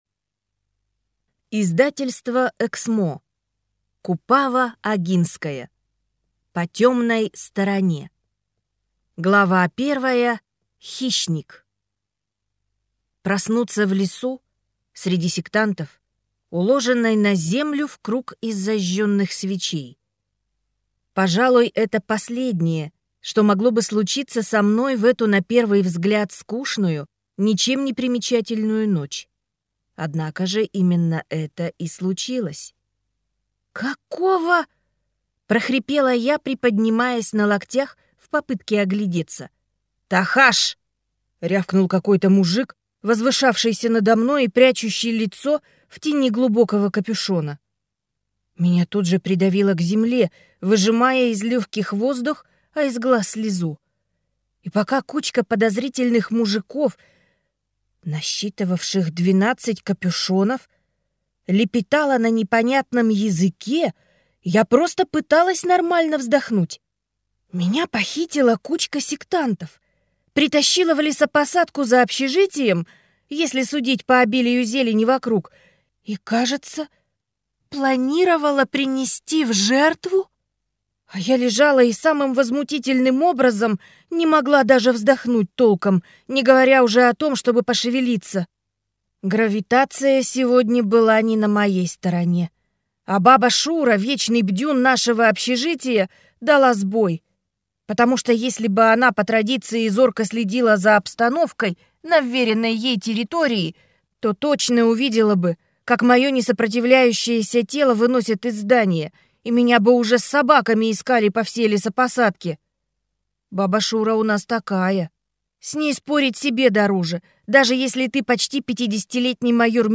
Аудиокнига По темной стороне | Библиотека аудиокниг
Прослушать и бесплатно скачать фрагмент аудиокниги